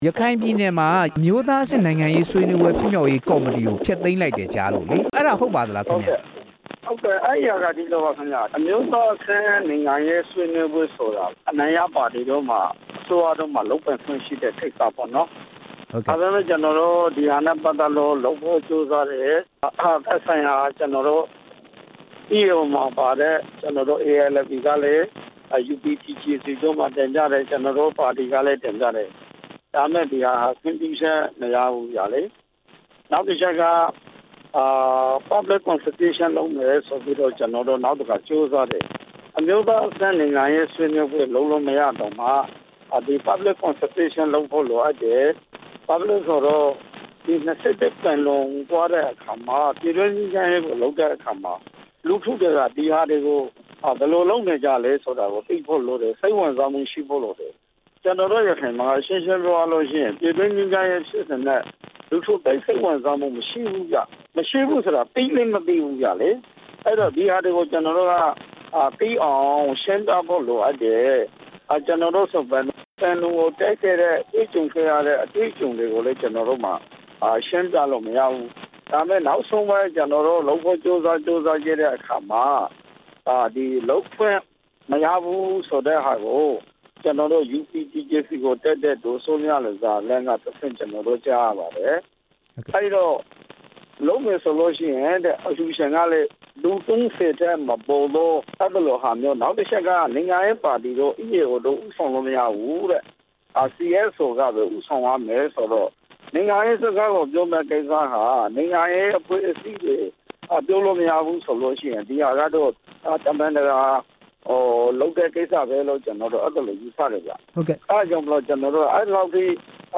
အမျိုးသားအဆင့်ဆွေးနွေးပွဲဖြစ်မြောက်ရေး ကော်မတီဖျက်သိမ်းမှု၊ ရခိုင်အမျိုးသားပါတီနဲ့ မေးမြန်းချက်